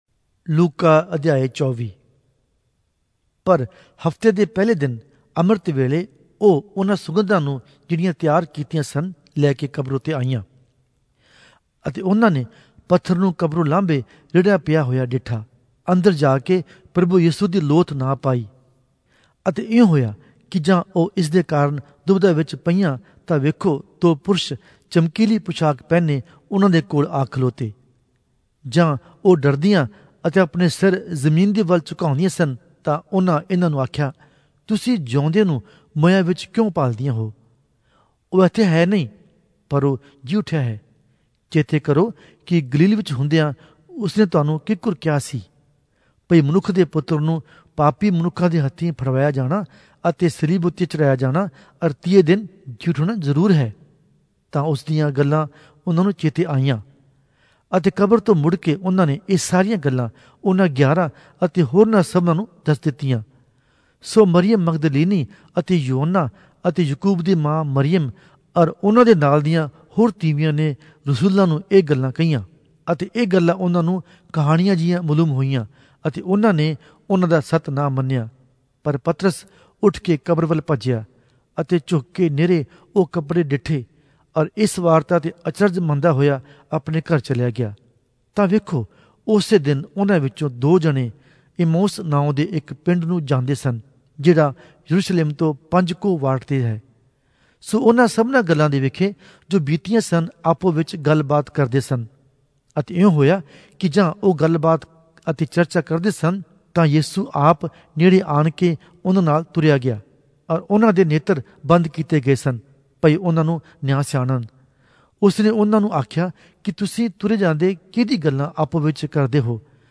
Punjabi Audio Bible - Luke 3 in Ervhi bible version